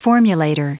Prononciation du mot formulator en anglais (fichier audio)
Prononciation du mot : formulator